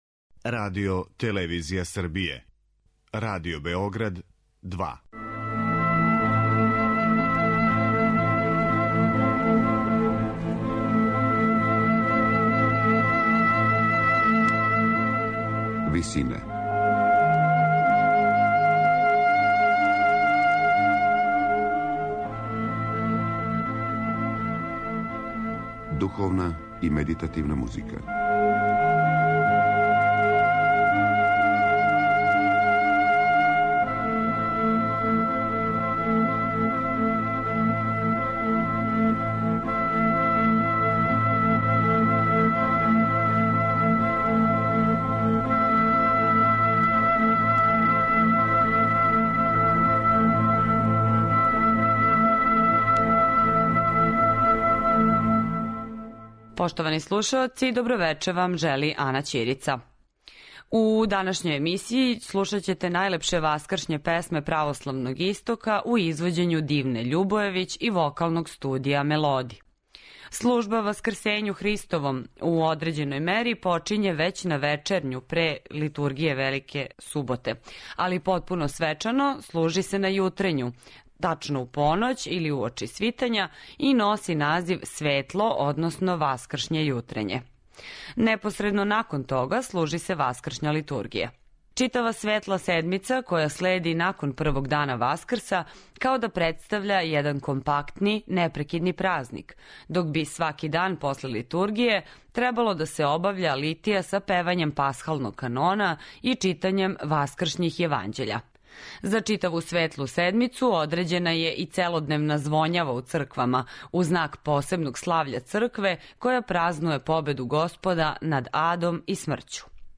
Слушаћете најлепше васкршње песме
Другог дана највећег хришћанског празника, у емисији Висине, која је посвећена духовној и медитативној музици, слушаћете најлепше васкршње песме православног Истока, у извођењу Дивне Љубојевић и вокалног студија „Мелоди".